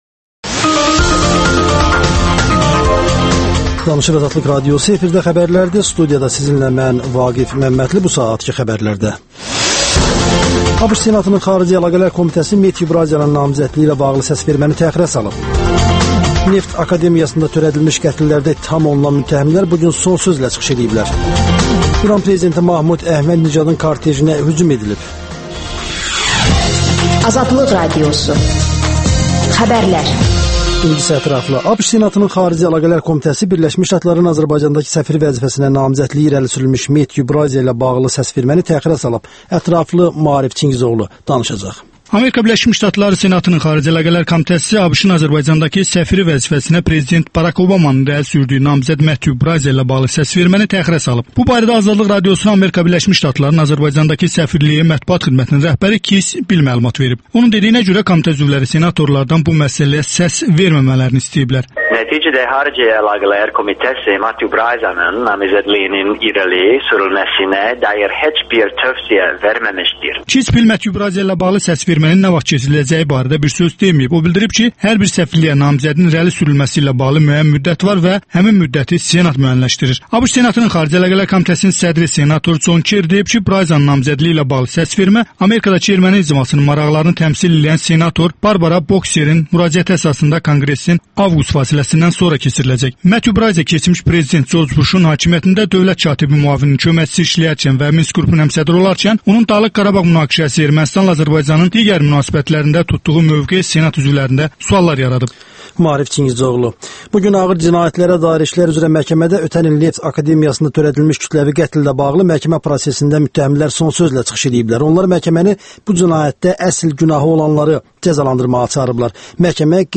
Tarixçi alim Musa Qasımlı və «Ümid» partiyasının sədri İqbal Ağazadə dəyişikliklərdən danışırlar